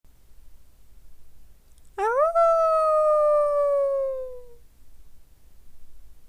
ガヤ①(犬?狼?鳴き声)